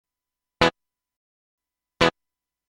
図A右は、SC-8850を直接USB接続した時の波形です。
実際の出音でも、微妙に違いが表われてます。（図A 右側の テイク１ と テイク５ を連続再生）
テイク１ は、アタックがある程度揃ってますが、テイク５ は僅かにバラけて聴こえます。
素材は、ギターの “Ｅコード” を鳴らしてるんですが、低音側と高音側とでは、音の立ち上がりが違うために、